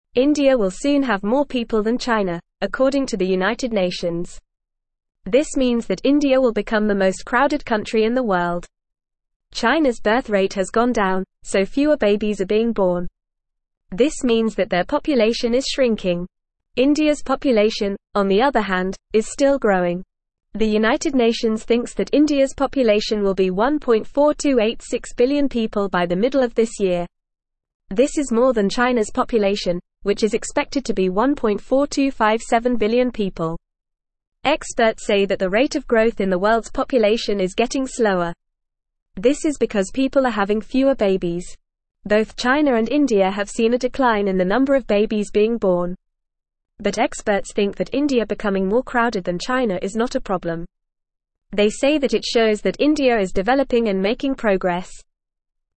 Fast
English-Newsroom-Beginner-FAST-Reading-India-to-Become-Most-Crowded-Country-Soon.mp3